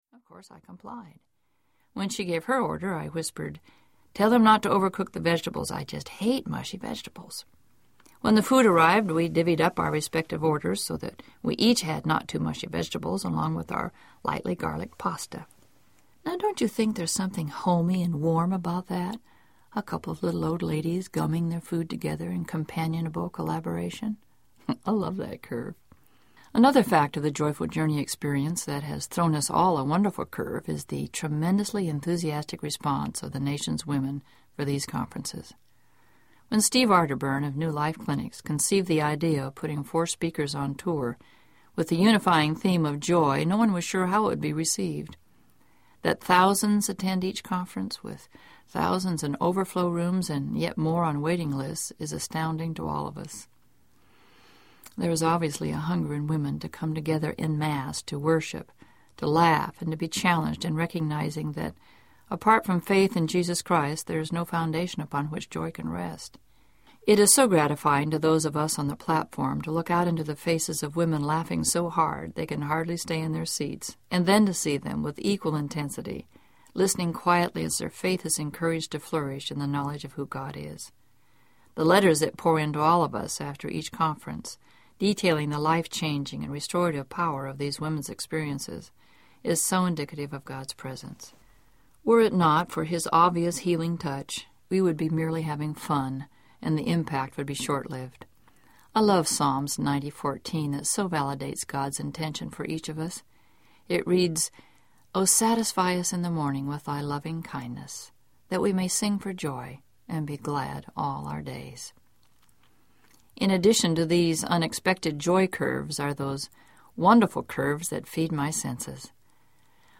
The Joyful Journey Audiobook